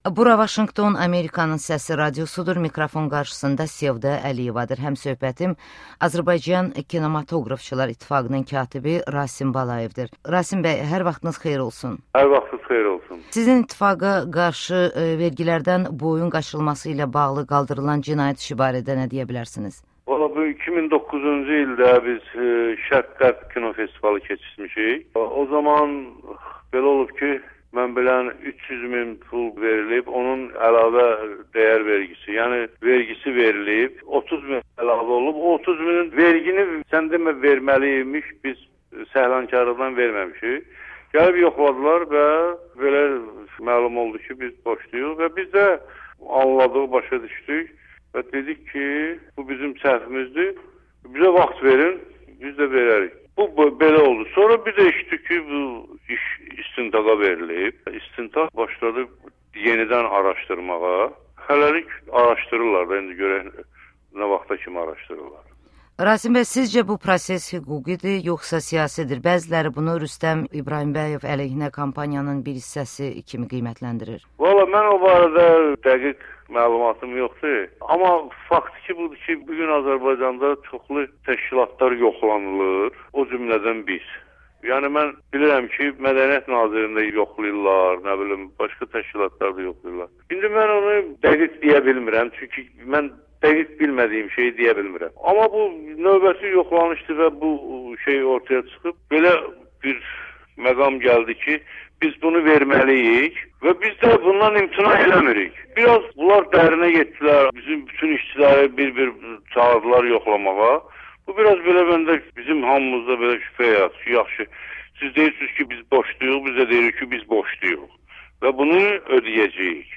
Rasim Balayevlə müsahibə